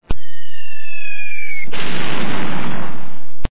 Military Sound Effects
The sound bytes heard on this page have quirks and are low quality.
IN-COMING ( IN-COMING ARTILLERY ROUND ) 3.36